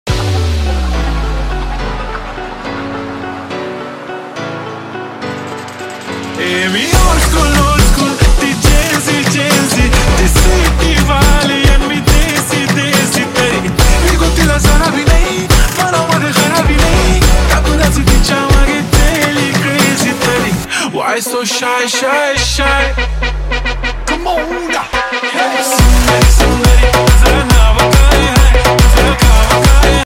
Marathi song